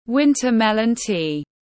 Trà bí đao tiếng anh gọi là winter melon tea, phiên âm tiếng anh đọc là /’wintə ‘melən ti:/
Winter melon tea /’wintə ‘melən ti:/